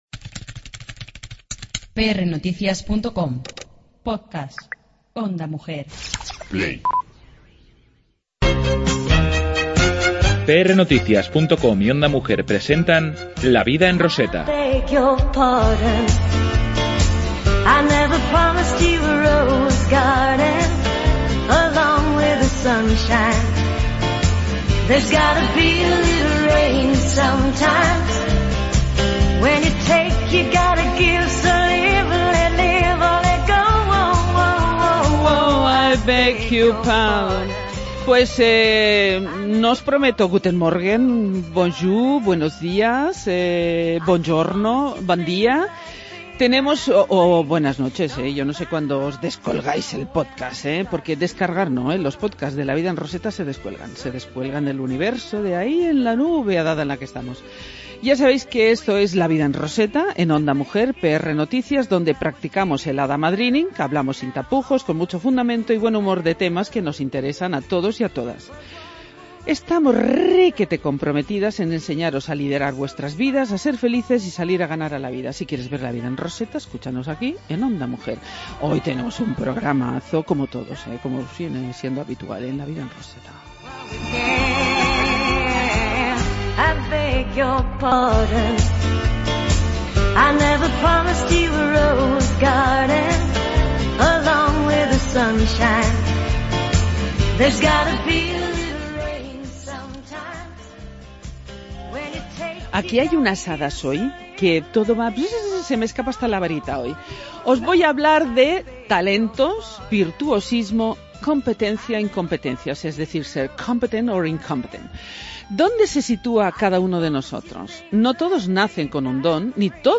En la sección Ya no tengo el alma en pena, entrevistamos a un hada del violín que se hizo manos